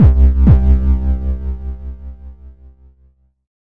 描述：好的循环。
Tag: 128 bpm Weird Loops Fx Loops 647.01 KB wav Key : C